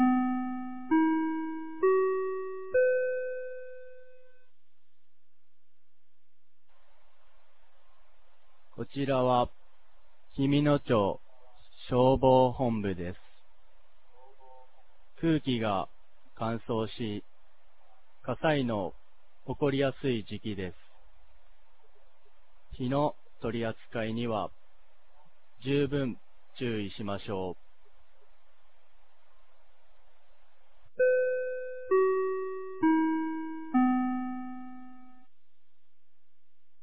2023年11月18日 16時00分に、紀美野町より全地区へ放送がありました。